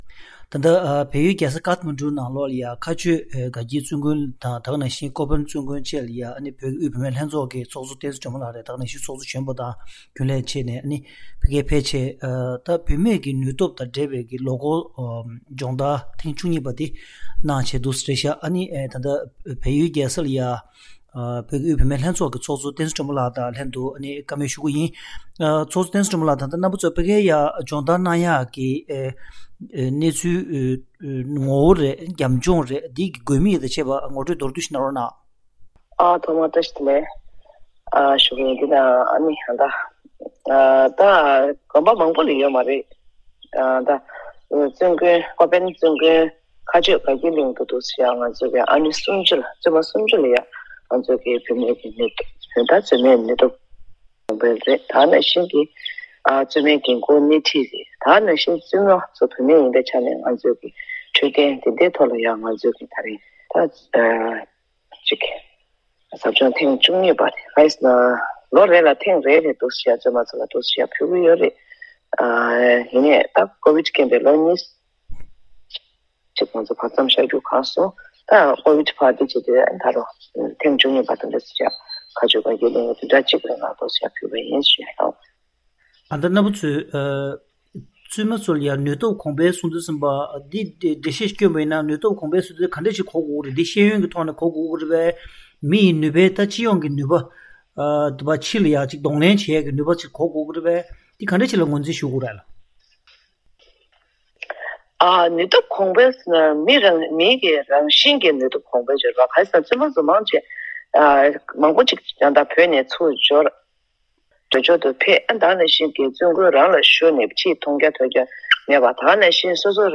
བཀའ་དྲི་ཞུས་པའི་ལས་རིམ་ཞིག་གསན་རོགས་གནང་།